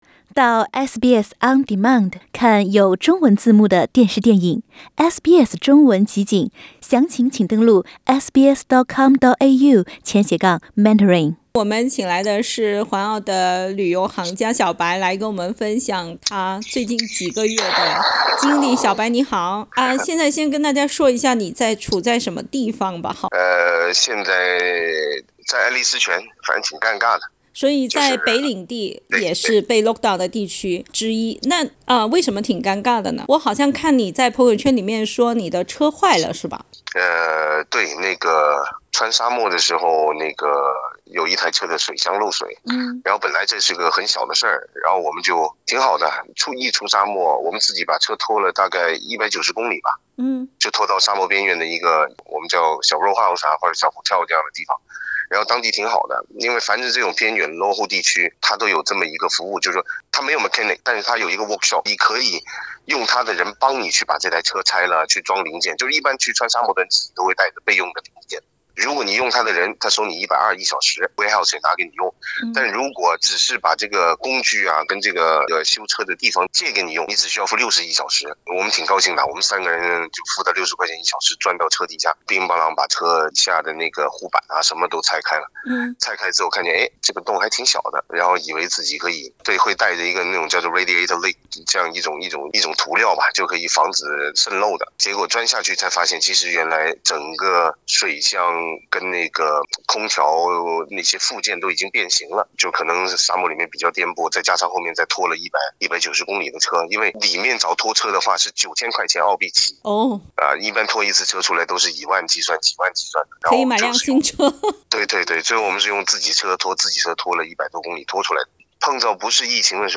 （请听采访） 北领地录得的一个新本地传播病例感染的是高传染性的新冠Delta变异病毒株。